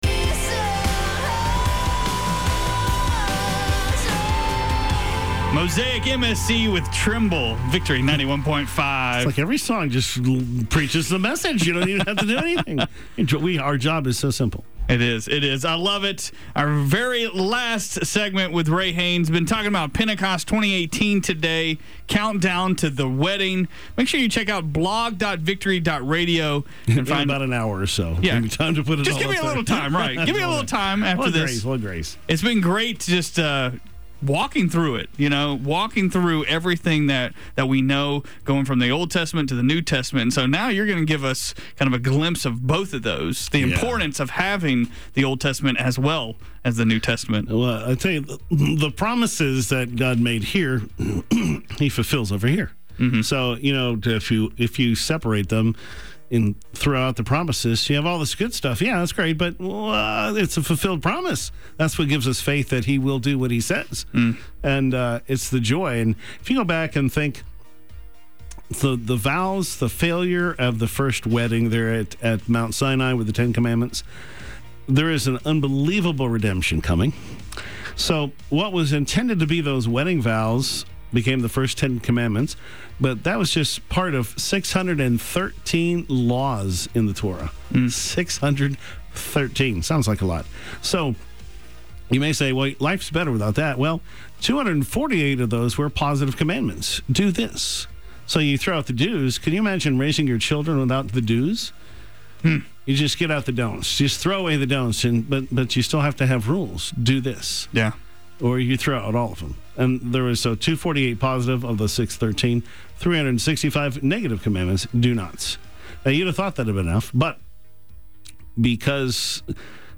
Live on-air clips and teaching notes